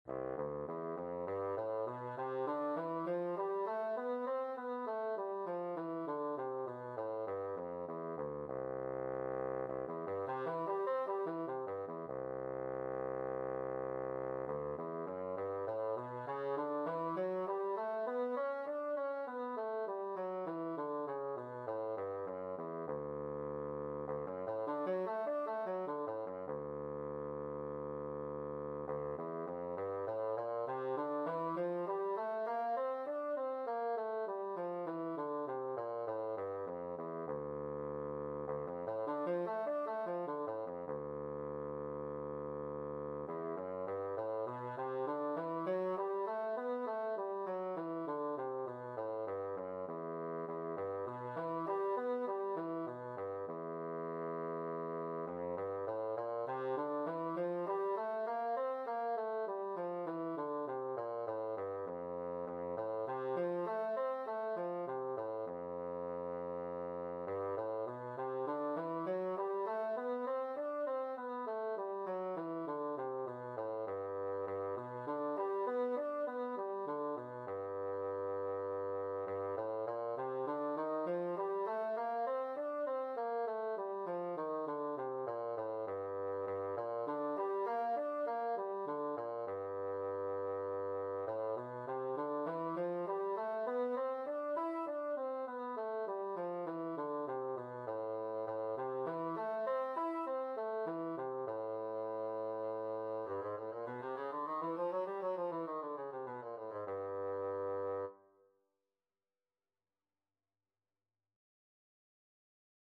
Bassoon scales and arpeggios - Grade 3
bassoon_scales_grade3.mp3